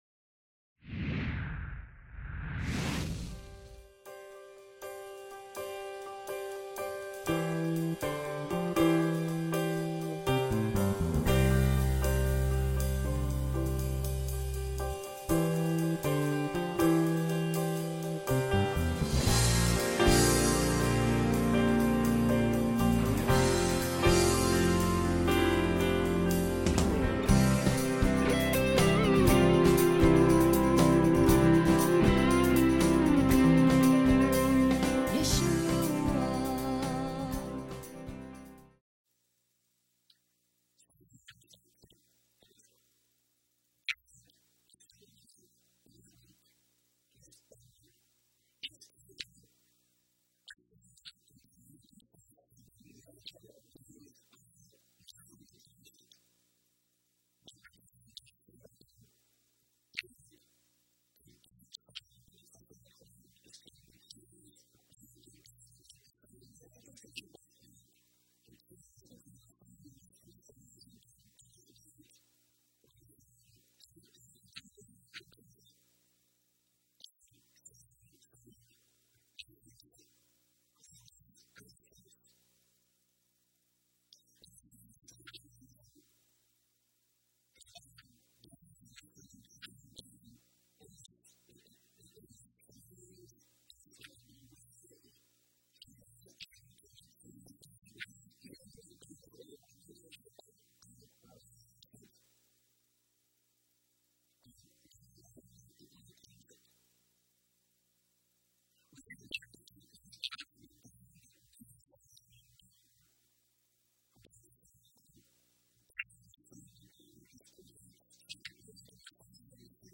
Lesson 4 Ch2 - Torah Class